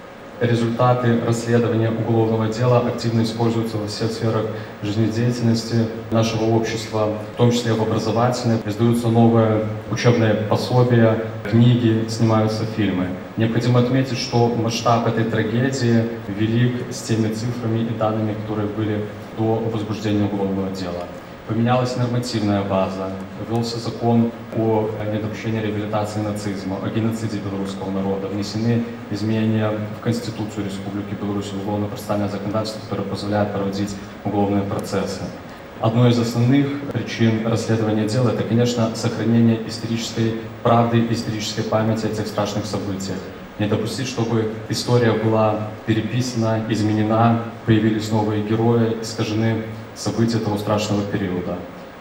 Обращаясь к ребятам, заместитель Барановичского межрайонного прокурора Станислав Давидавичус сообщил, с апреля 2021-го ведется расследование уголовного дела о геноциде белорусского народа в годы войны. За пять лет проделана большая работа – опрошены более 20 тысяч свидетелей тех страшных событий, установлены новые, ранее неизвестные, места массового уничтожения мирных граждан.